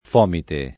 fomite [ f 0 mite ] s. m.